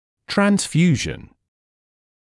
[træns’fjuːʒn][трэнс’фйуːжн]трансфузия, переливание (крови), гемотрансфузия